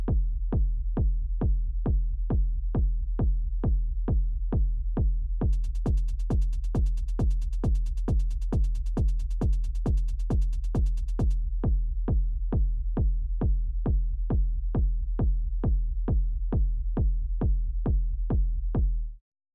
I don’t really notice a decrease of the kick volume when the hats starts but it sounds to increase when they stop.
When the hats come in, the kick gets masked a bit, try to filter the hats with an high pass filter (some hats have frequency content way down into the mid-lows).